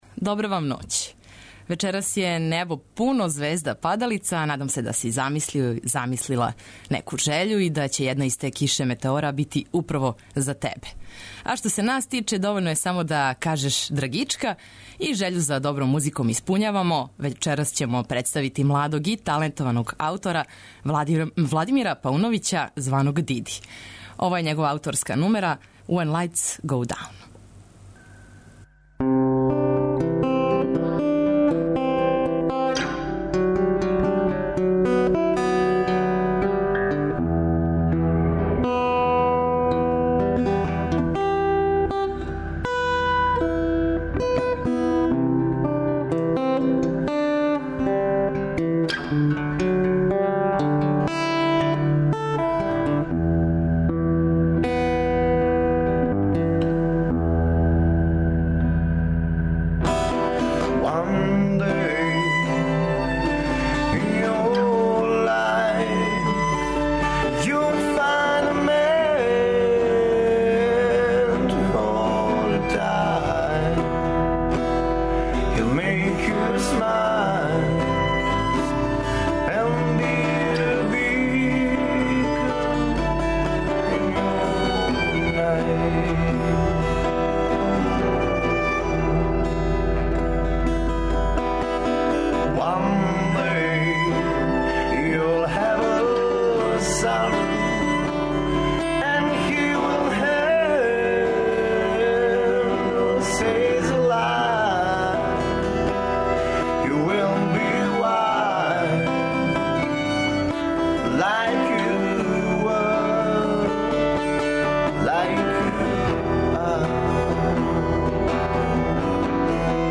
Свираће уживо своје песме, а преслушаваћемо и остатак ауторског рада, као и музику по његовом избору. Летње вече уз реге ритмове, сањиви, а моћан 'грув', слушамо се од поноћи до један!